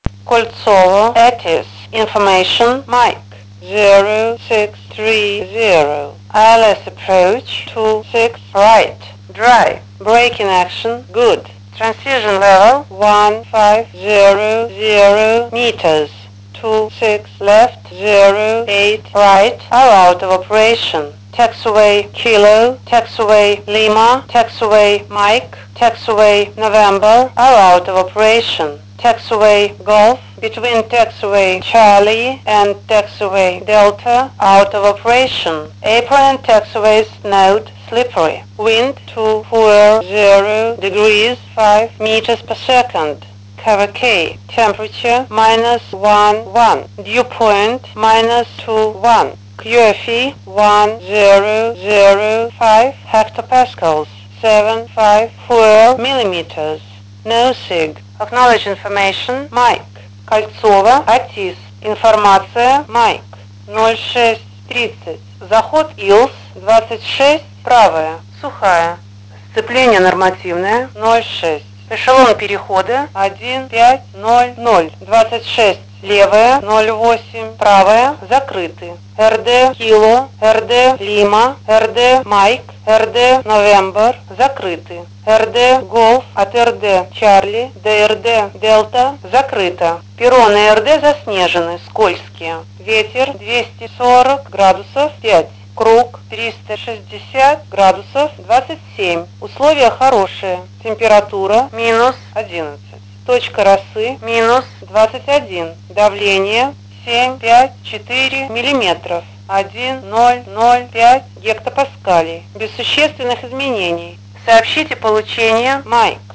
АТИС аэропорта "Кольцово", Екатеринбург, 2010г.
Начало » Записи » Записи радиопереговоров - авиация
Зима-весна 2010 г. Писалось на скорую руку на R20.